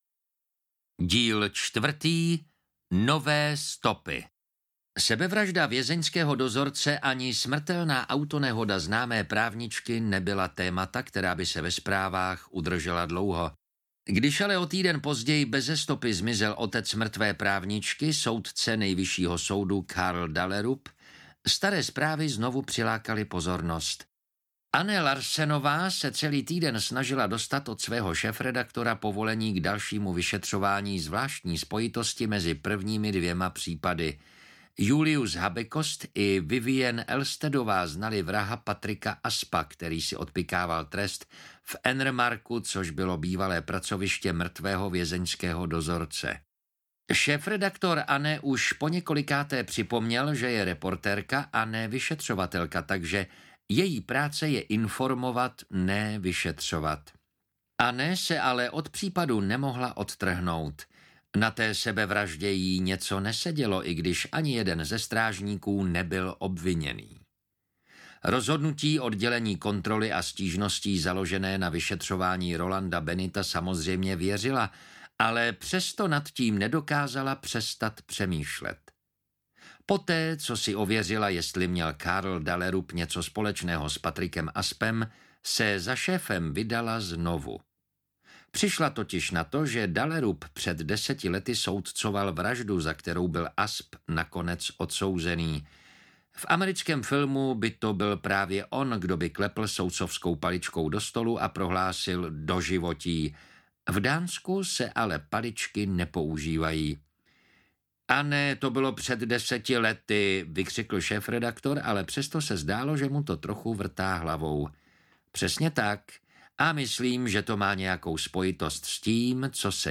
Uklízeč 4: Nové stopy audiokniha
Ukázka z knihy